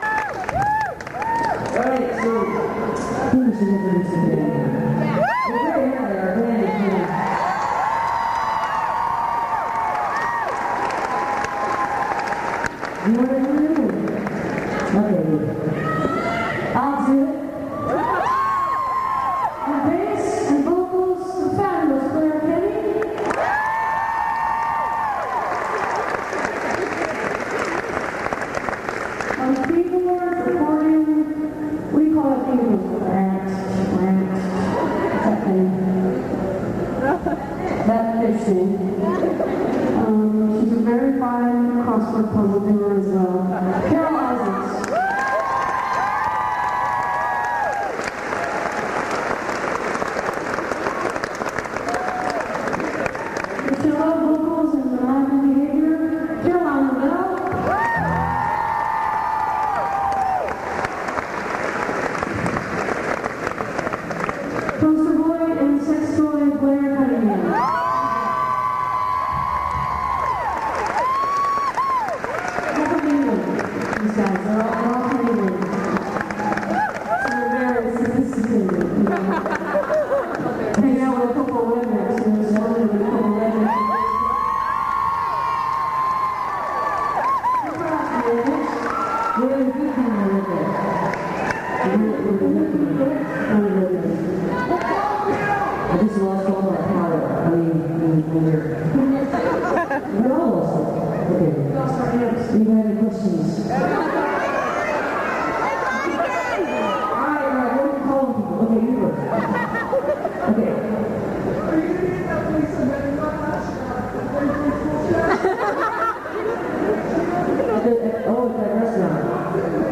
09. band introductions (5:52)